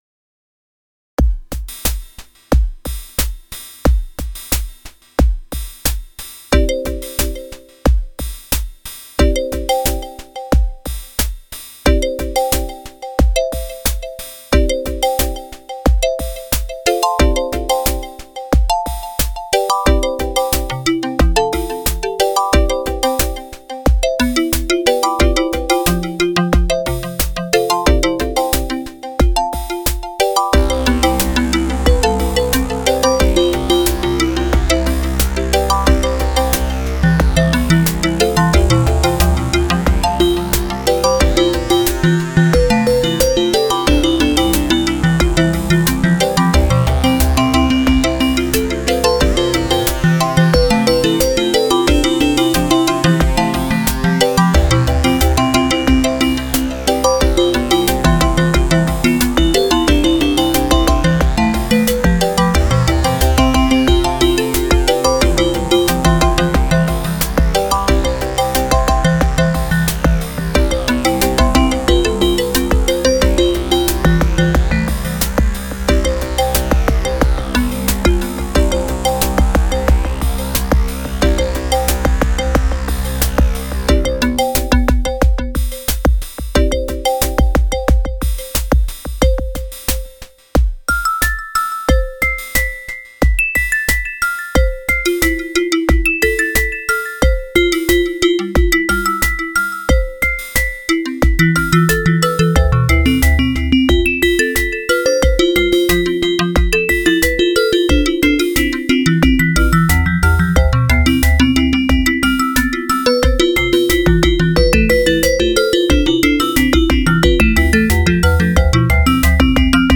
YouTube Demo of the new Button/Duo-LED switch matrix, supported by MIDIbox SEQ V3 (the stereo MP3 version is